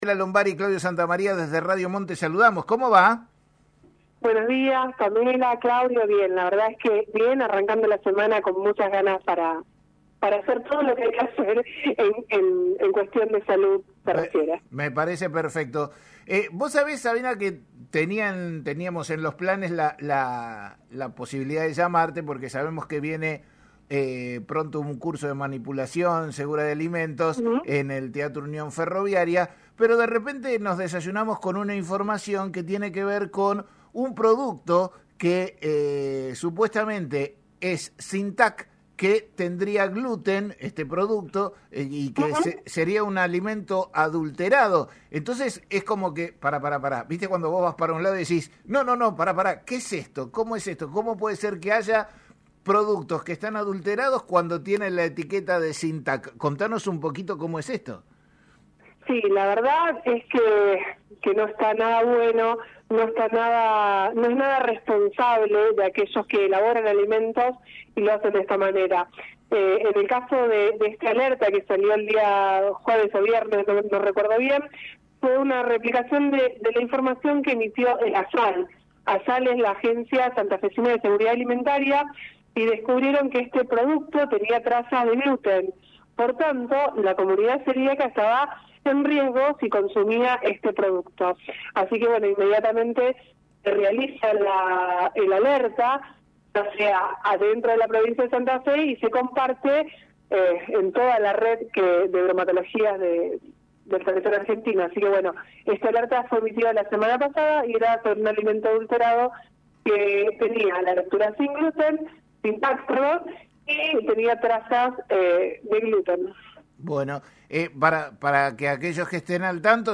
En una reciente entrevista en el programa «La Mañana de la Radio» emitido por LT35 Radio Mon Pergamino